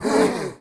client / bin / pack / Sound / sound / monster / skeleton_magician / damage_2.wav
damage_2.wav